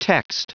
Prononciation du mot text en anglais (fichier audio)
Prononciation du mot : text